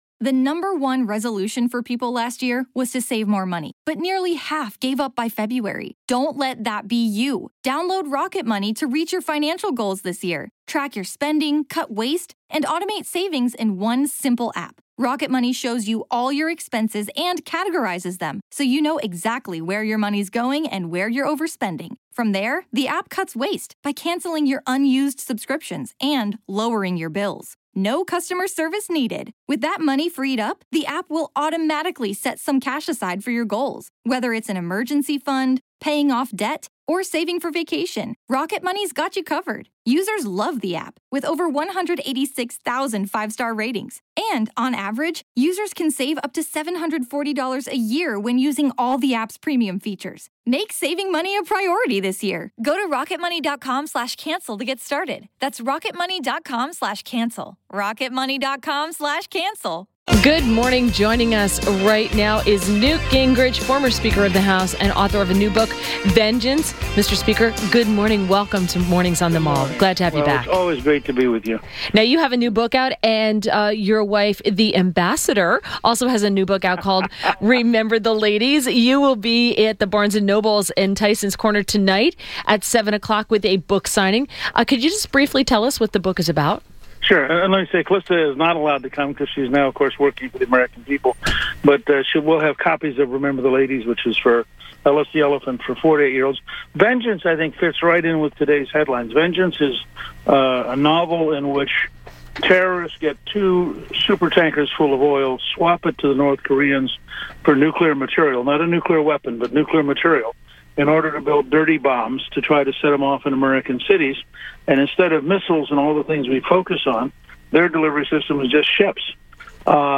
WMAL Interview - NEWT GINGRICH - 10.11.17